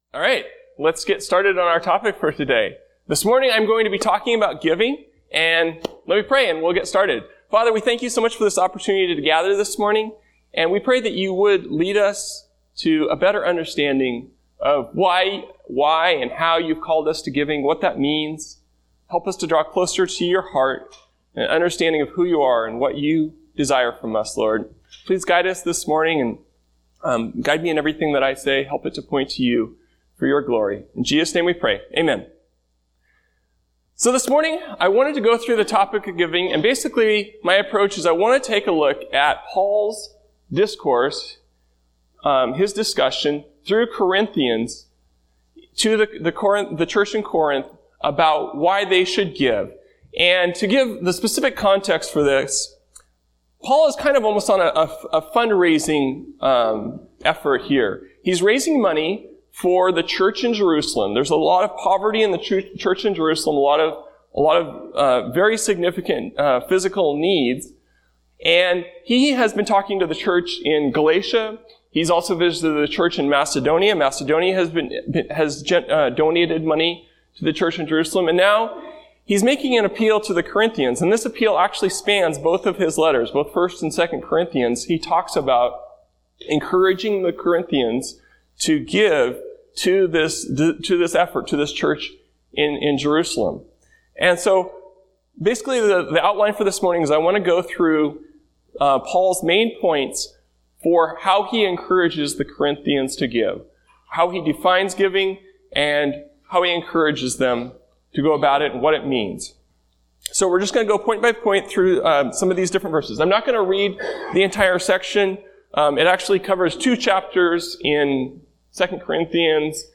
Sermons by Series | Sandy Ridge Church